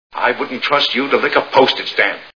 Mr Smith Goes to Washington Movie Sound Bites